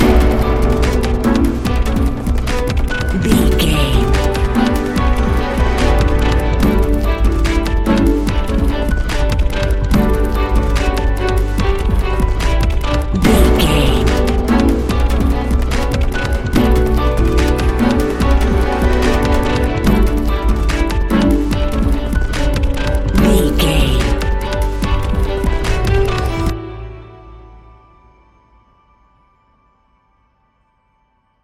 Ionian/Major
electronic
techno
trance
synthesizer
synthwave